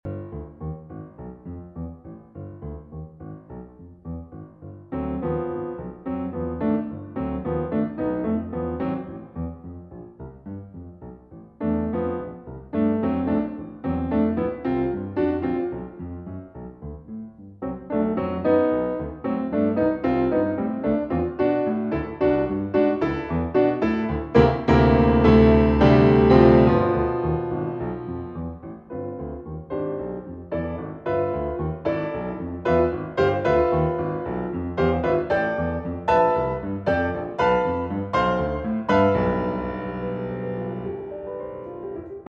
piano
eight miniatures with lyrical and engaging melodies